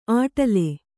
♪ āṭale